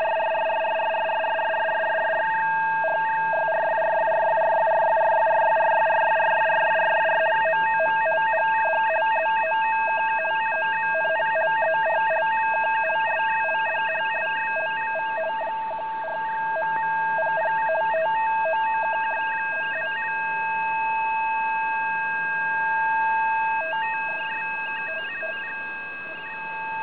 MCVFT-systems (Multichannel VFT)
CIS 3 x BAUDOT 50 Bd